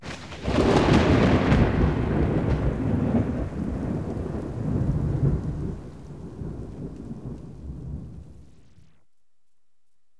Menu_Thunder.wav